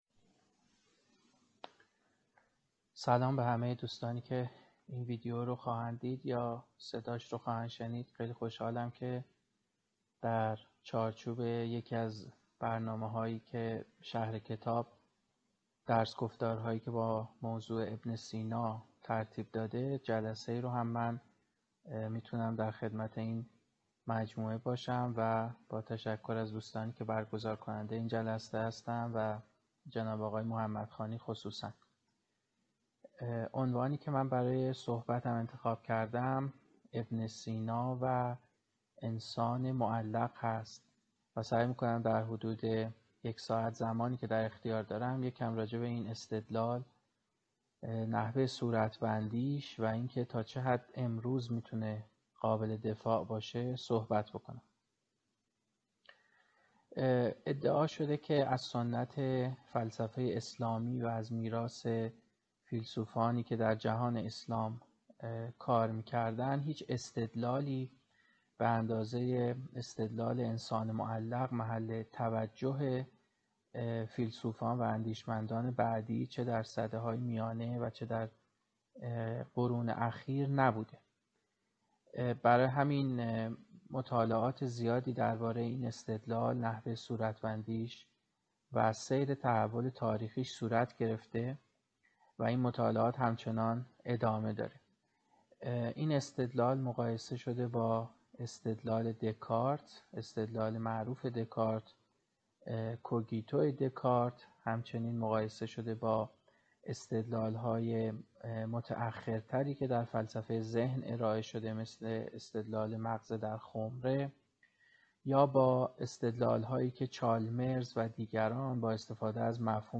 جلسه بیستم از درس‌گفتارهایی درباره بوعلی‌سینا | بنیاد علمی و فرهنگی
این درس‌گفتار به صورت مجازی از اینستاگرام شهر کتاب پخش شد.